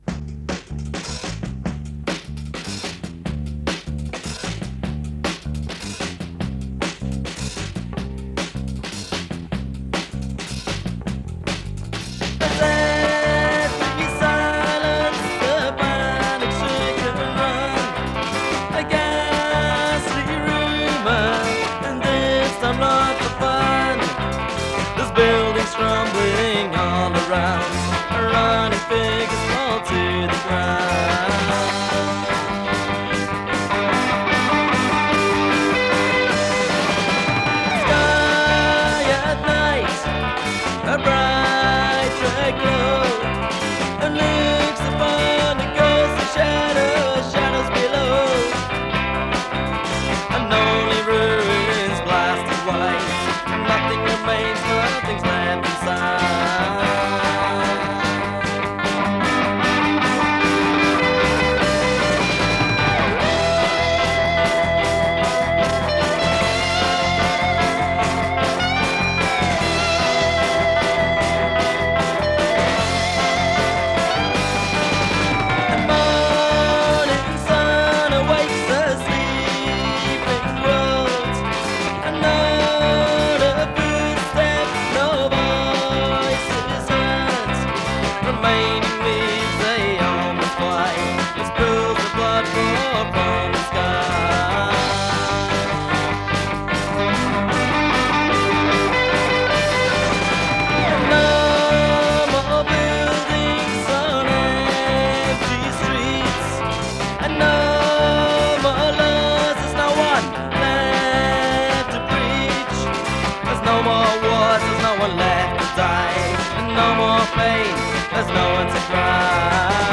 cutec 4-track portastudio - at the YMCA